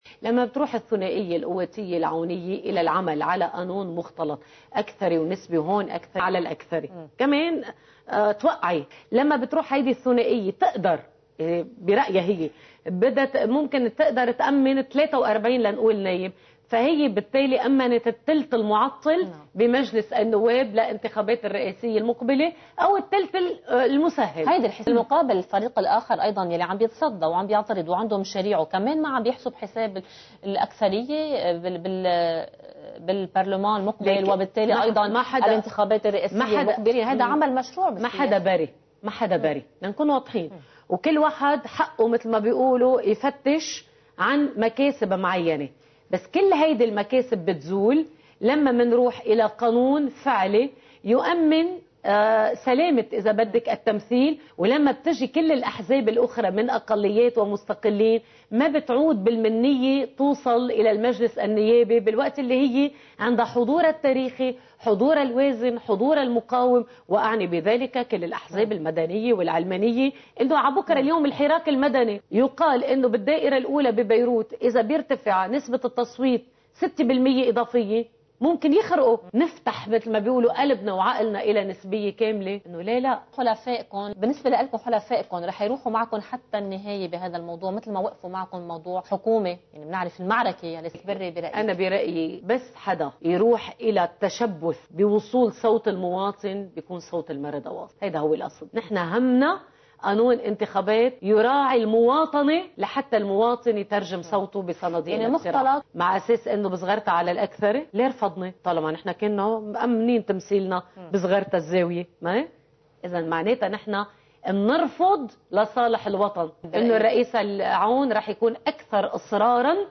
مقتطف من حديث القيادية في “المردة” فيرا يمين لقناة “الجديد”: